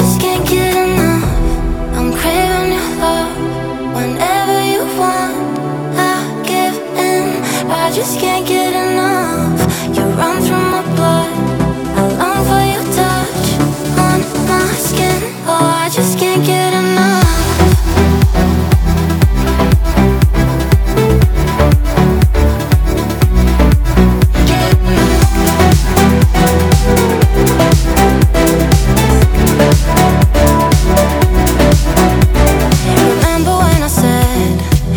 Dance
Жанр: Танцевальные